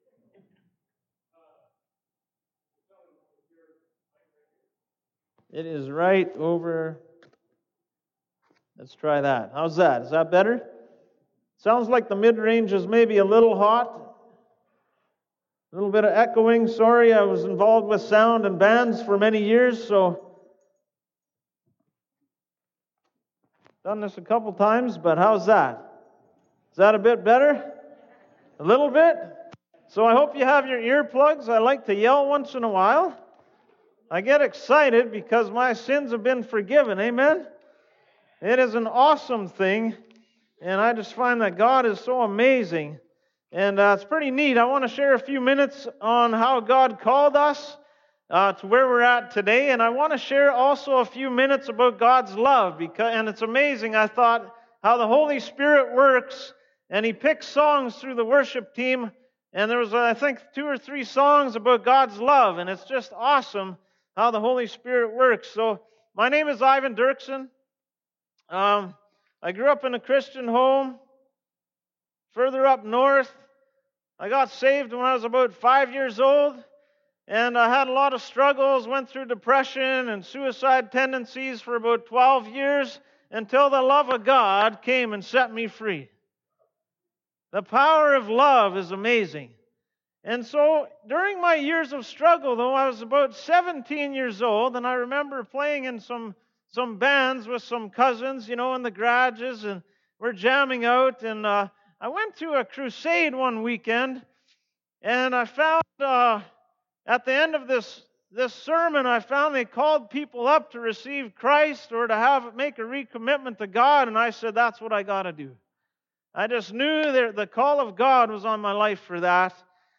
Sermons | Westgate Alliance Church